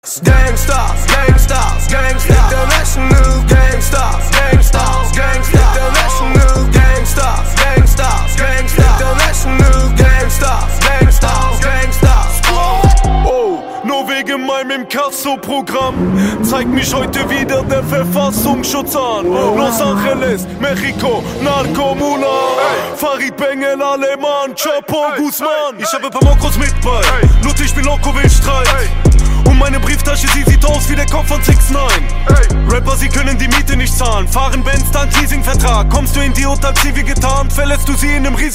Siempre los últimos tonos de Reguetón